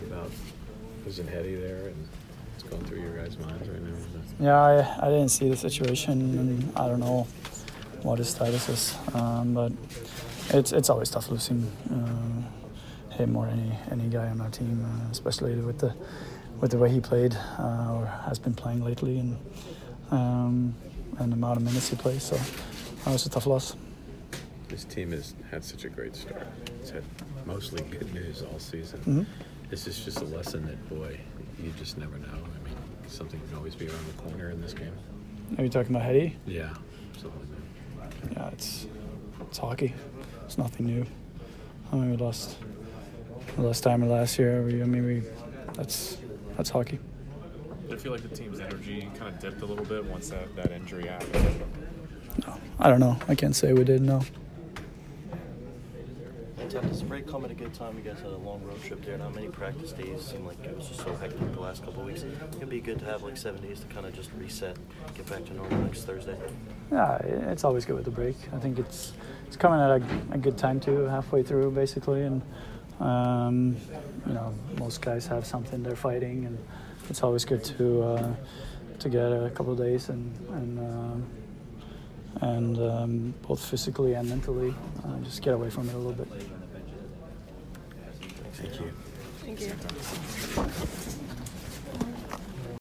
Anton Stralman Post-Game 1/11